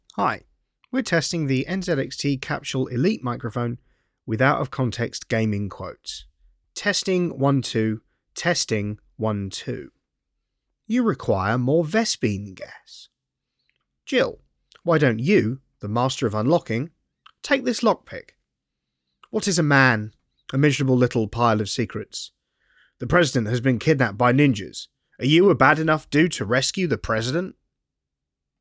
• Loại mic: Condenser cardioid
Capsule Elite tạo ra âm thanh ấm áp, vượt trội hơn hẳn so với các micro tai nghe truyền thống, ngay cả ở phân khúc cao cấp.
Capsule-Elite-Audio-Test.mp3